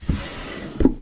打开 关闭 " 木质抽屉 O
Tag: 打开 关闭 关闭 抽屉